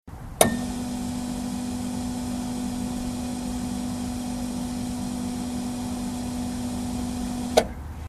Elevator lift
Category: Sound FX   Right: Personal
Tags: Elevator Sounds Elevator Elevator Sound clips Elevator sound Sound effect